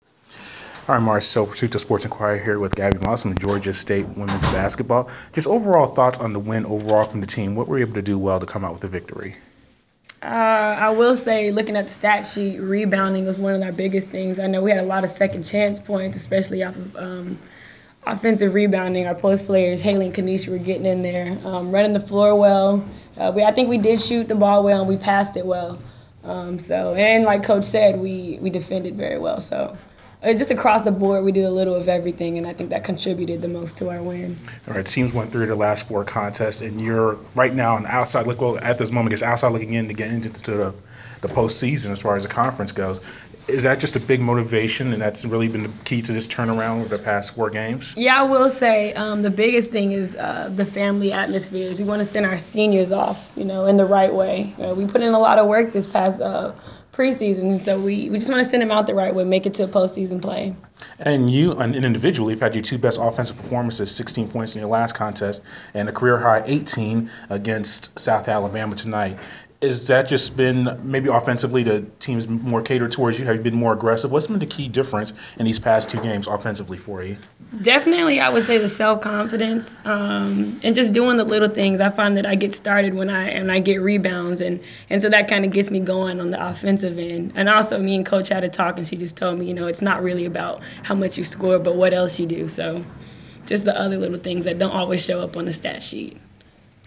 Inside the Inquirer: Postgame interview